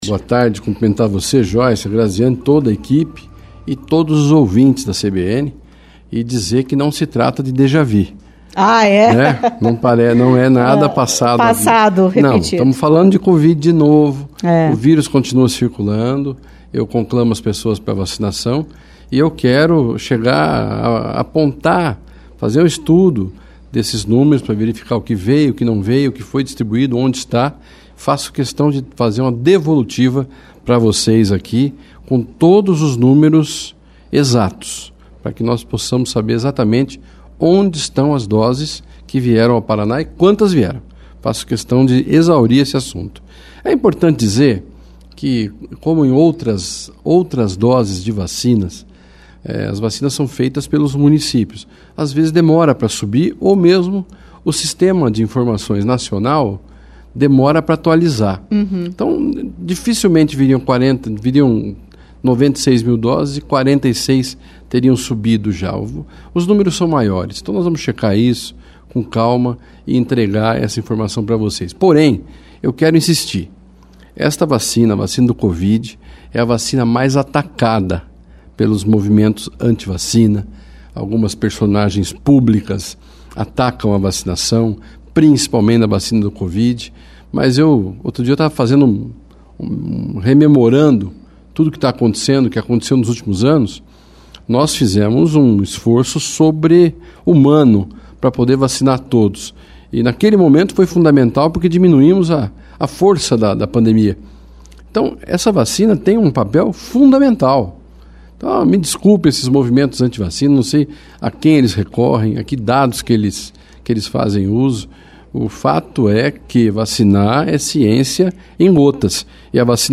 O secretário de Estado da Saúde, Beto Preto, esteve na CBN Curitiba nesta segunda-feira (25).